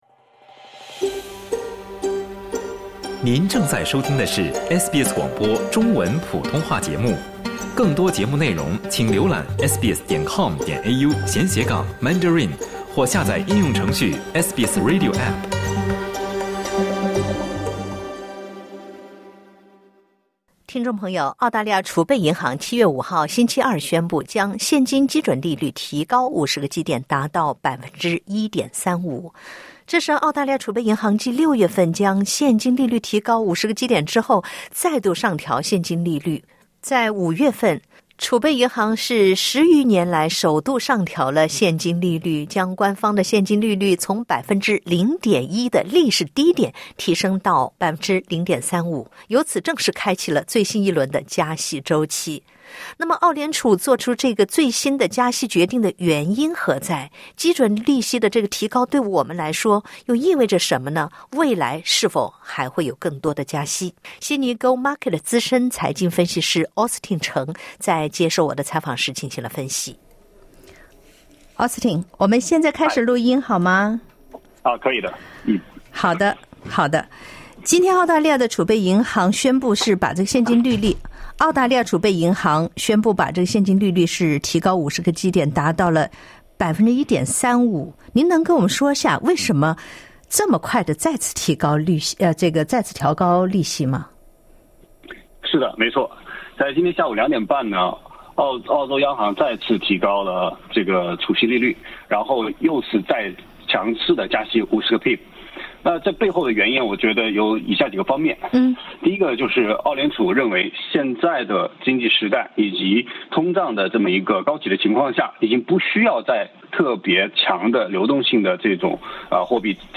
財經分析師認為，這是澳聯儲對保持澳元穩定性所寀取的一次防守性加息。（點擊圖片收聽報道）